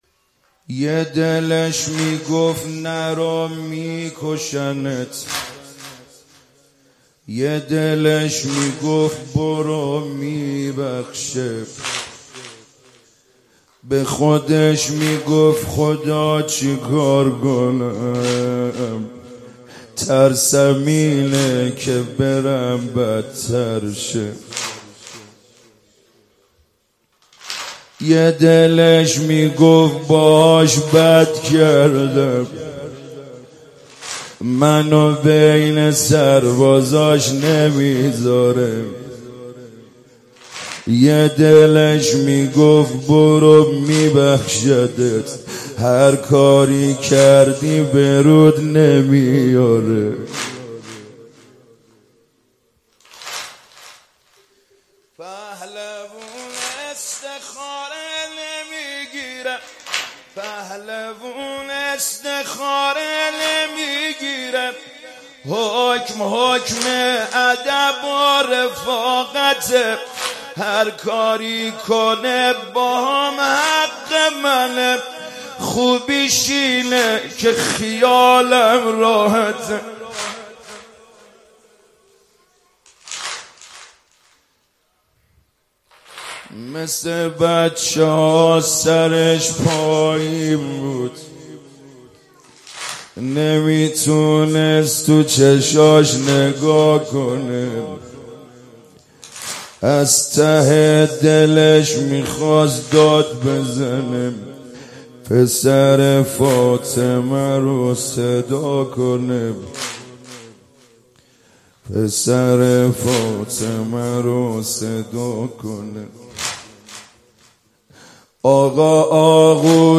شب چهارم محرم97 هیات کربلا رفسنجان